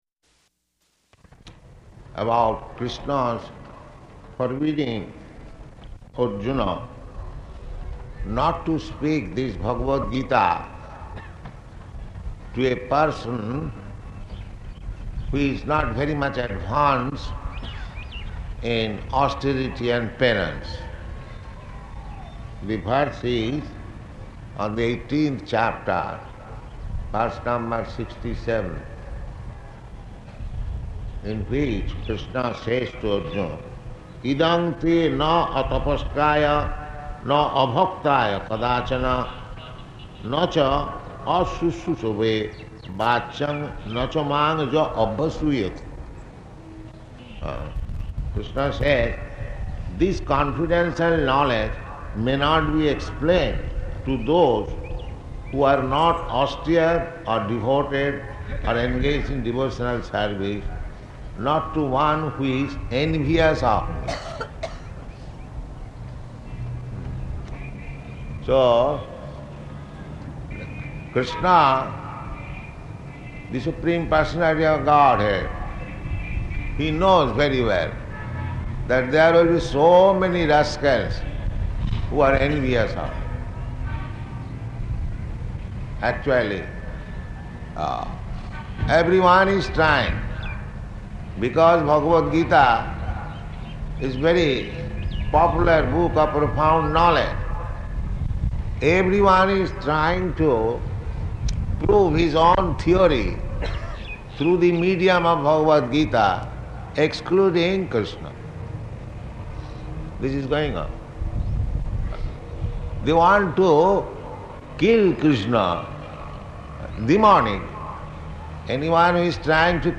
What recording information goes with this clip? Location: Ahmedabad